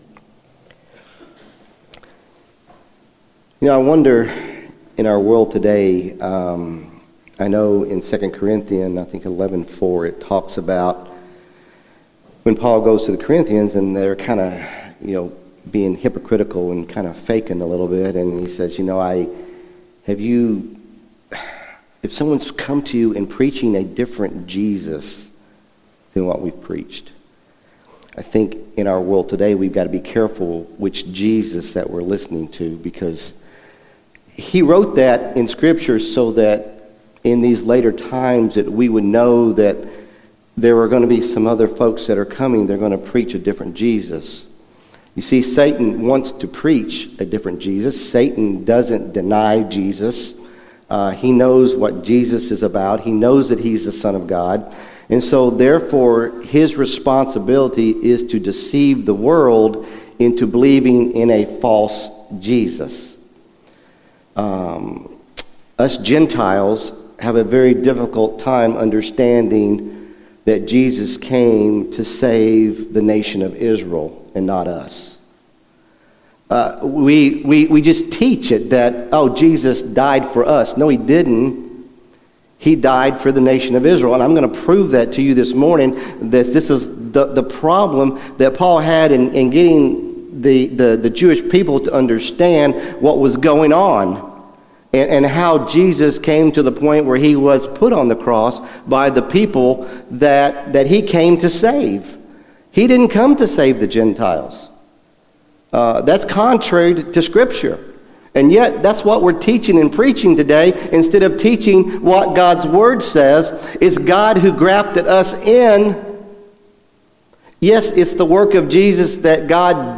Sermon April 27, 2025 | South Elkhorn Baptist Church